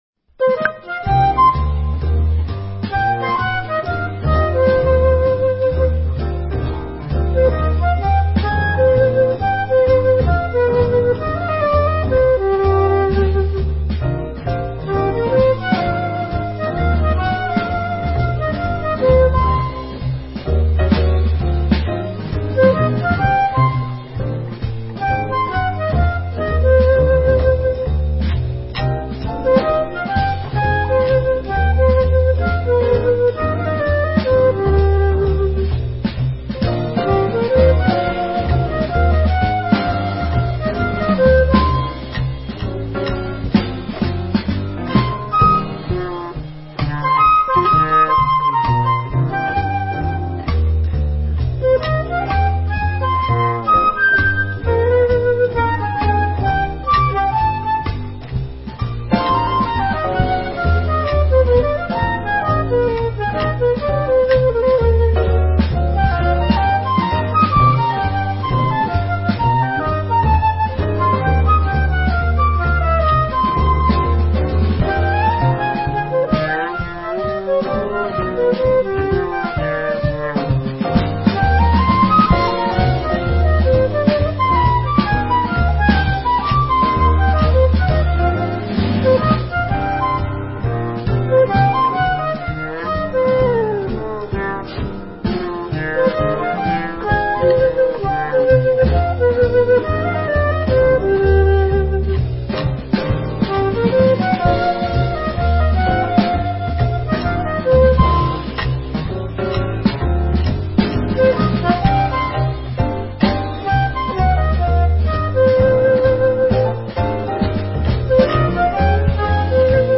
Musica da Camera